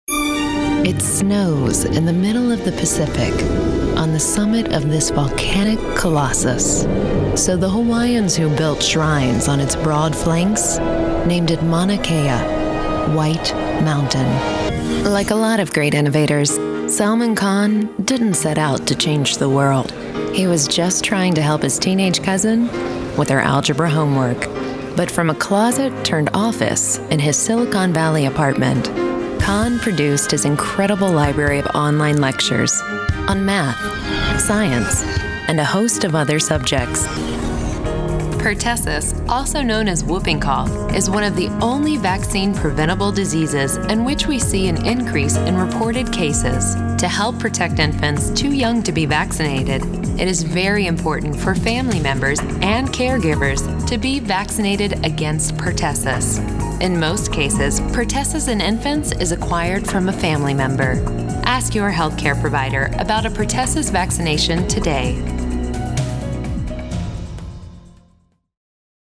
narration : women